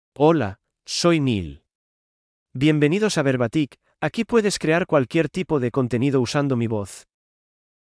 NilMale Spanish AI voice
Nil is a male AI voice for Spanish (Spain).
Voice sample
Listen to Nil's male Spanish voice.
Male
Nil delivers clear pronunciation with authentic Spain Spanish intonation, making your content sound professionally produced.